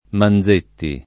[ man z% tti ]